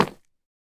Minecraft Version Minecraft Version latest Latest Release | Latest Snapshot latest / assets / minecraft / sounds / block / deepslate_bricks / step2.ogg Compare With Compare With Latest Release | Latest Snapshot
step2.ogg